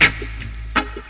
RIFFGTR 20-L.wav